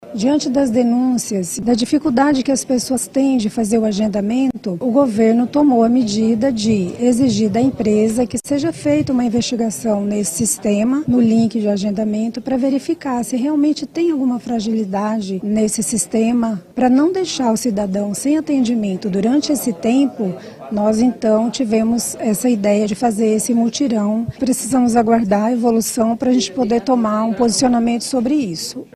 A medida foi tomada após identificar uma falha no sistema on-line de agendamento para a retirada da nova CIN, desenvolvido e de responsabilidade da empresa contratada para prestar o serviço, como explica Margateth Vidal, diretora do Departamento de Polícia Técnico Cientifico.